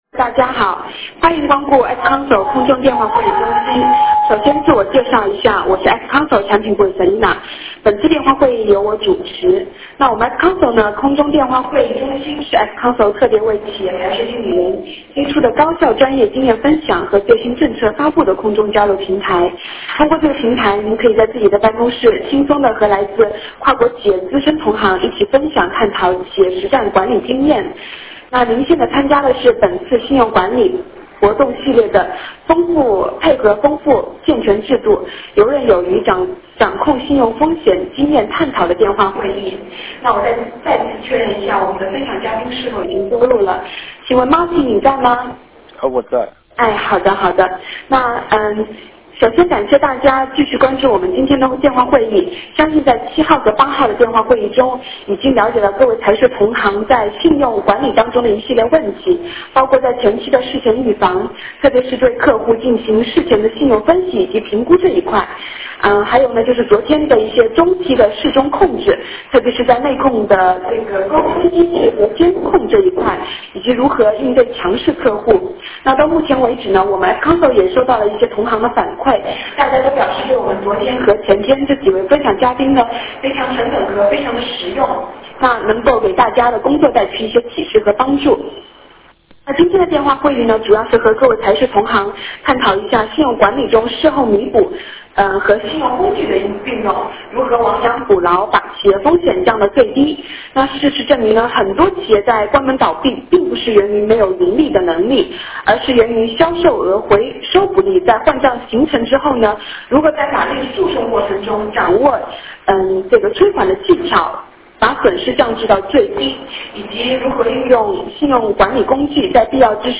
形式： 电话会议